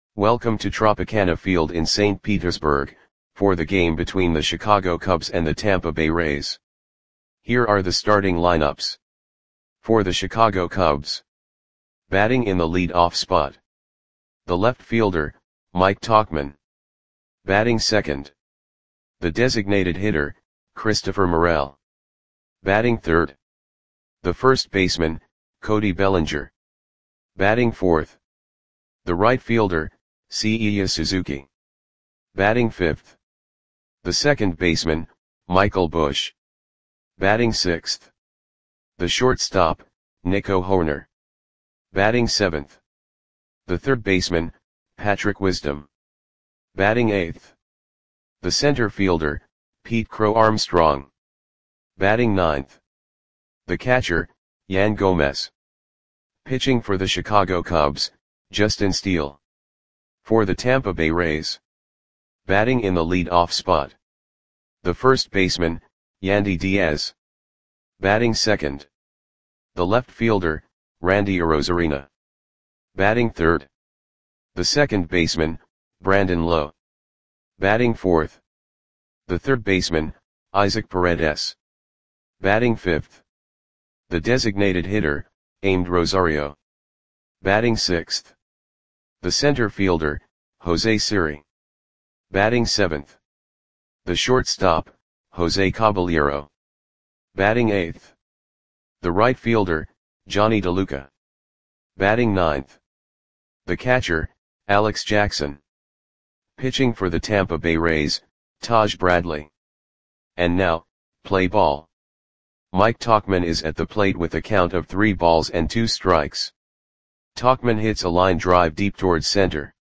Audio Play-by-Play for Tampa Bay Rays on June 13, 2024
Click the button below to listen to the audio play-by-play.